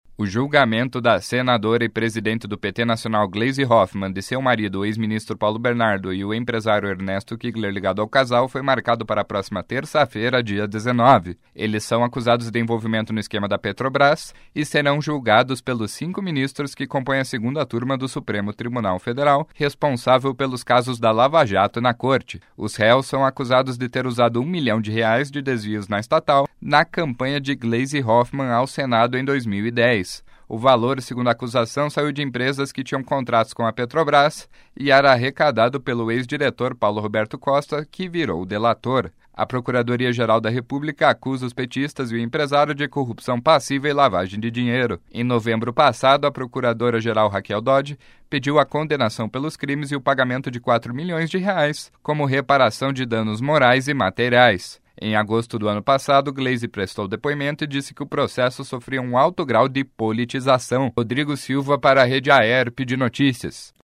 13.06 – BOLETIM SEM TRILHA – Julgamento de Gleisi e Paulo Bernardo é marcado para o dia 19